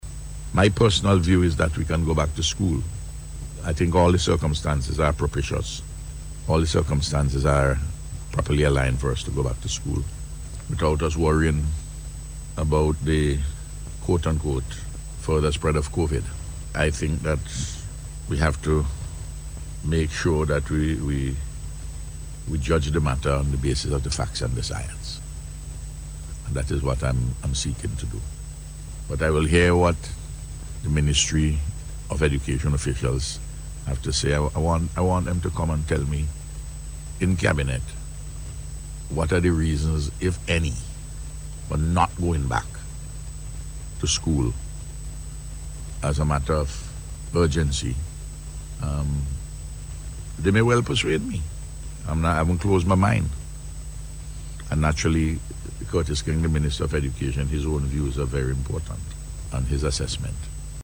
This was among matters addressed by Prime Minister Gonsalves on NBC Radio this morning.